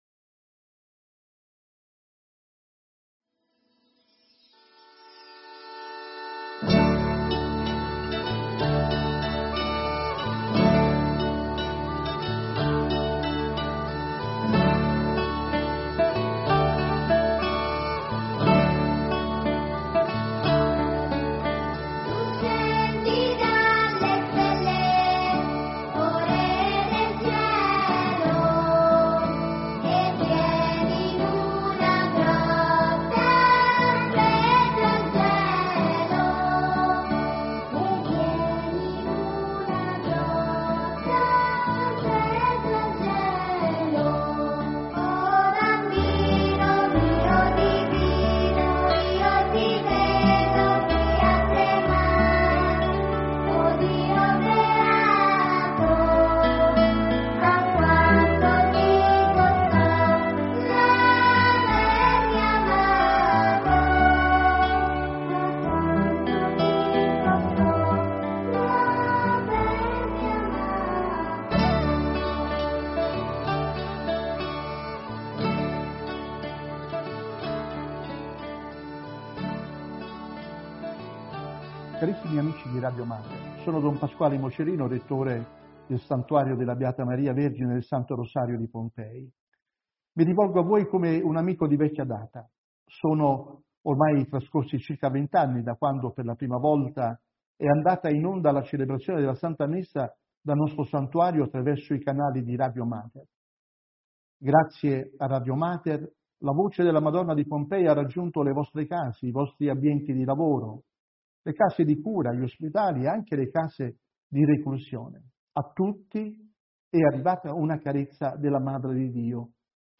Auguri natalizi dai conduttori di Radio Mater